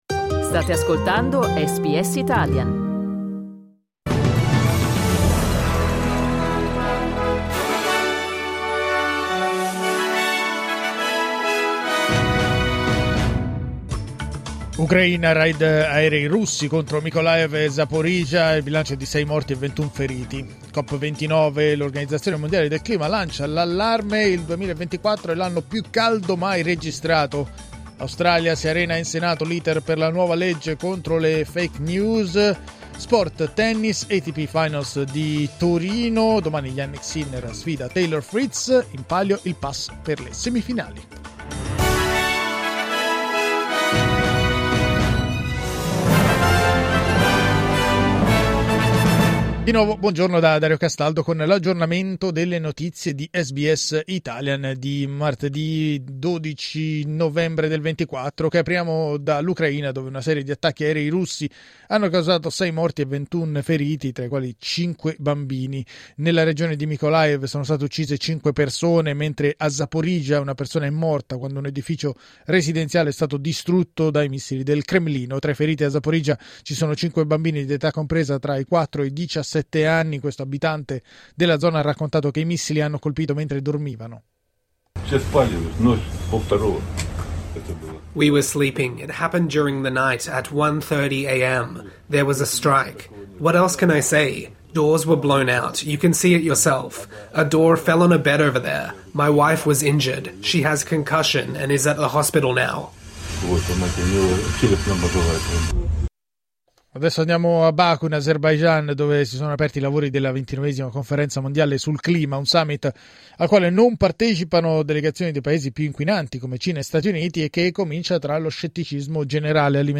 News flash martedì 12 novembre 2024
L’aggiornamento delle notizie di SBS Italian.